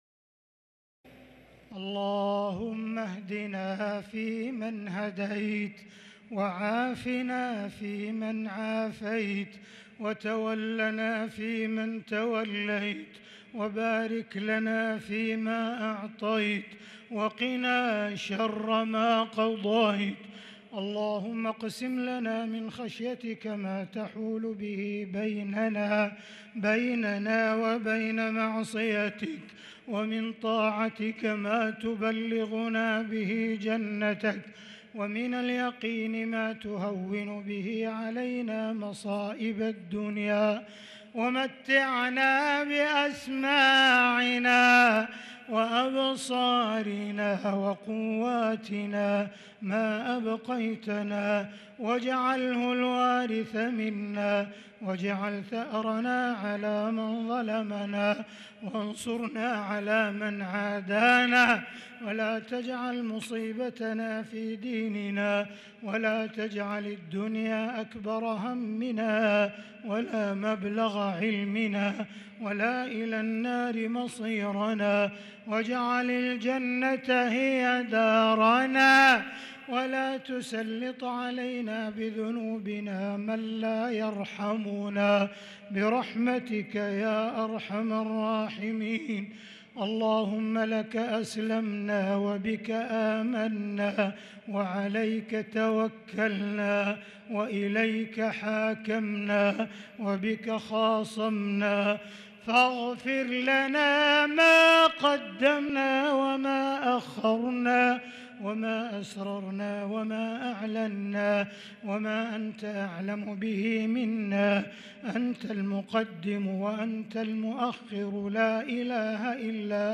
دعاء القنوت ليلة 23 رمضان 1443هـ | Dua for the night of 23 Ramadan 1443H > تراويح الحرم المكي عام 1443 🕋 > التراويح - تلاوات الحرمين